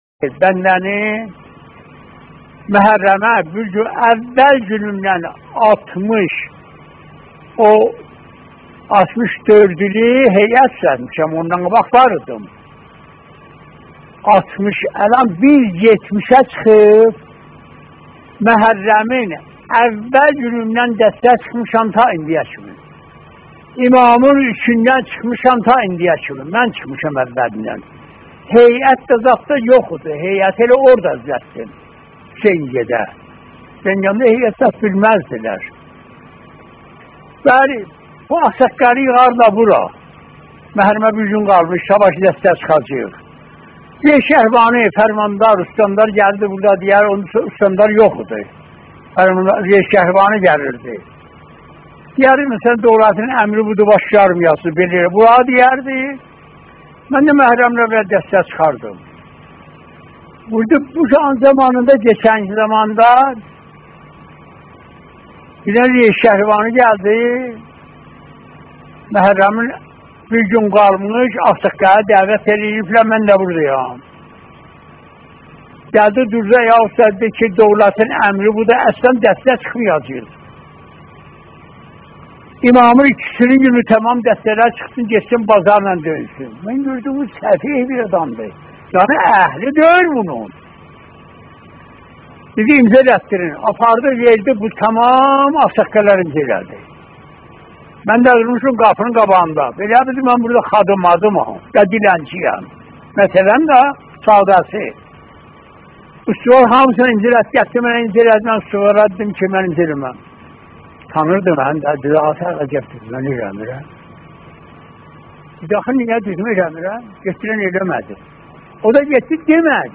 سخنرانی ،کلیپ، سخنرانی، مداحی، قرآن، نماهنگ، انیمشین، آموزش مداحی، آموزش قرآن،
سخنرانی ترکی